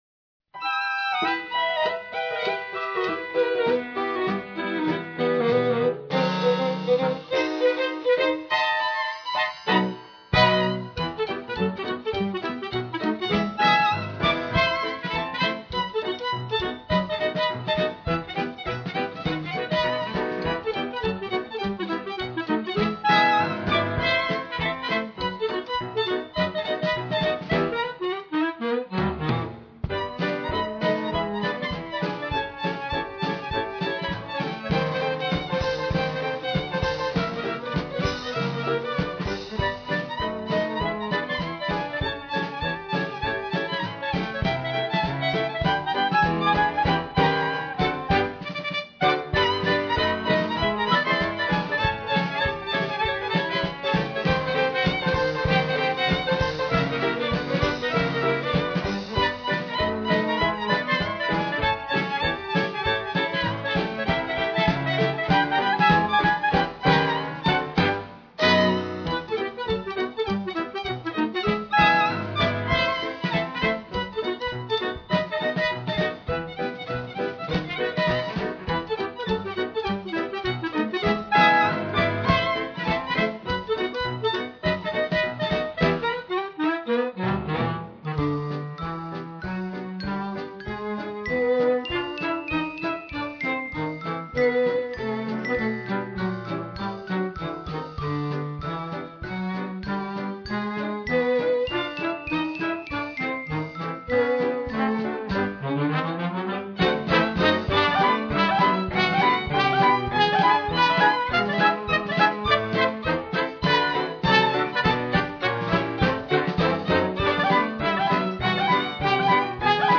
ragtime dances
a ragtime two-step.